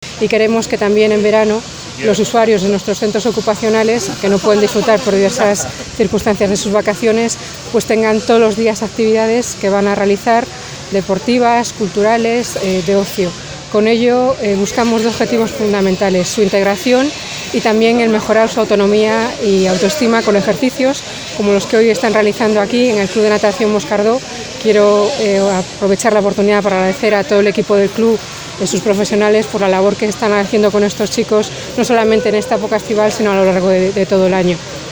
según explicó la viceconsejera (archivo mp3) formato MP3 audio(1,33 MB).